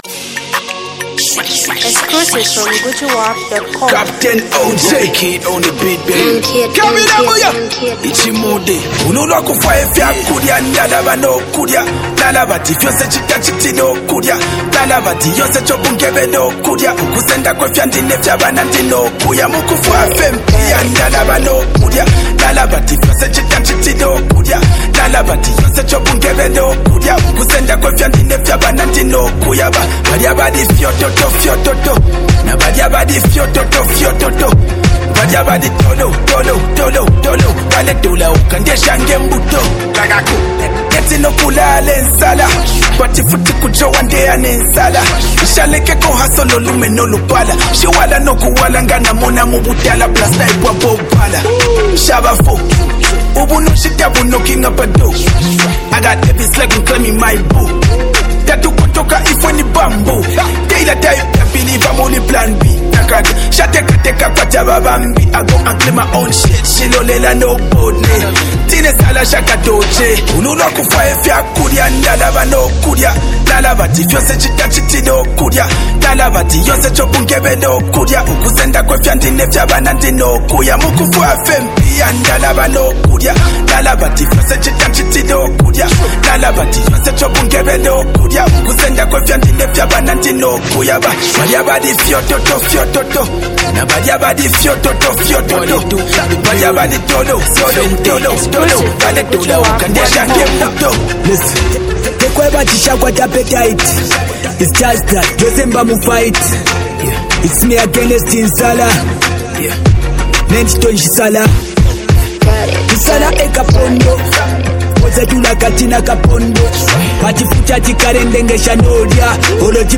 a vibrant new track
creates an irresistible beat.
Infectious Energy